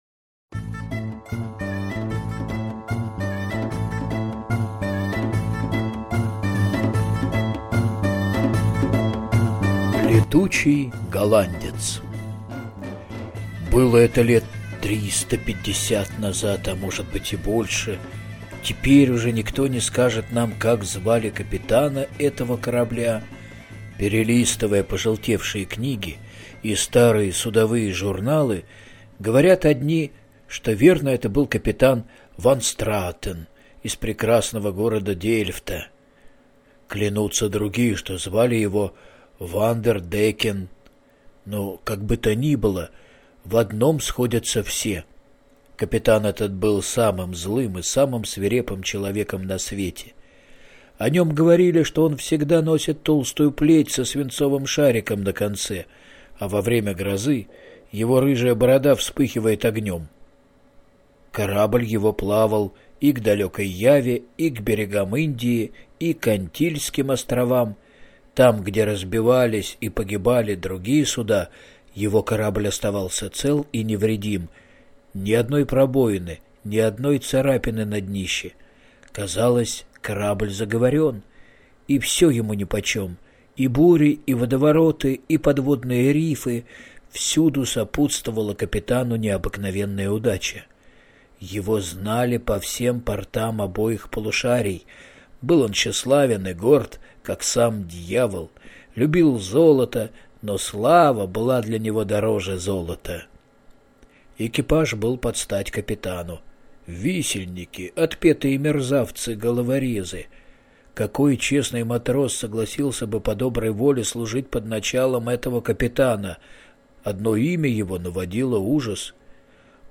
Летучий голландец - аудио легенда - слушать онлайн